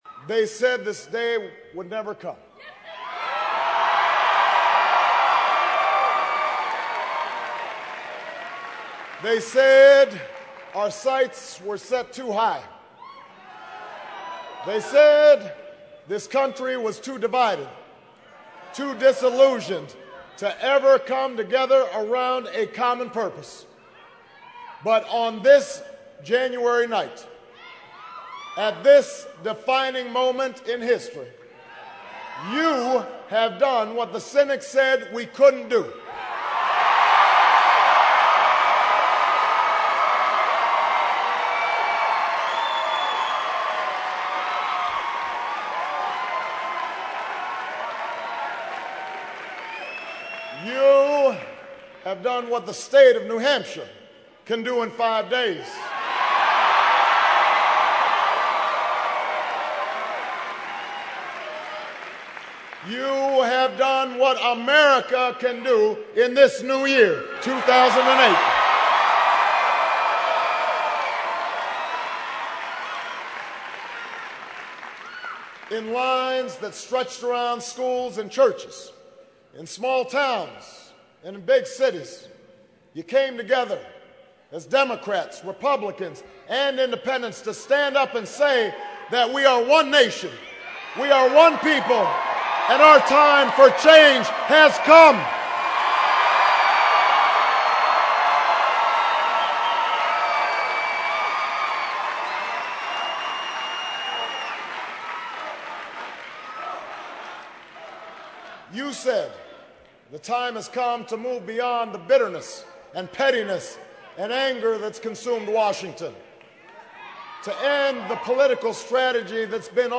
Audio: MIke Huckabee speech to supporters 10:17 MP3Audio: Barrack Obama speaks to supporters. 12:50 MP3
obama speech.mp3